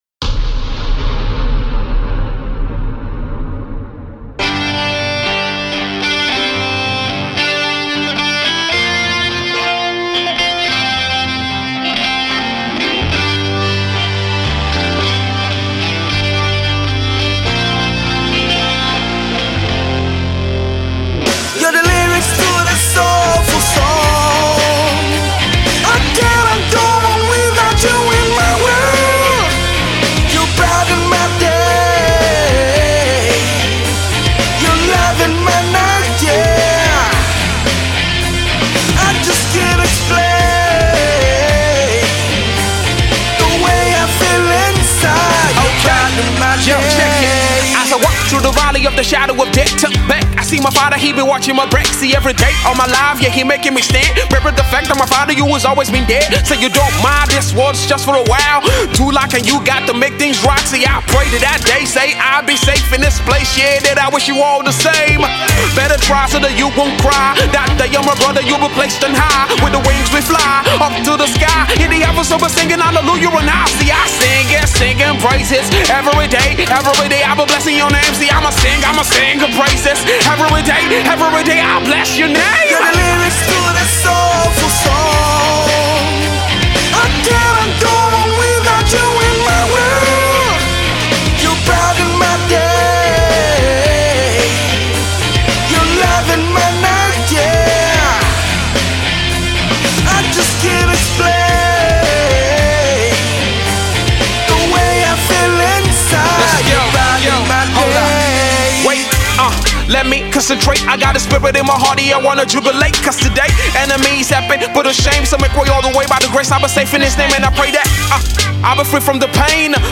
remix version
Hip Hop artiste
It’s a rock song with a blend of hip-hop.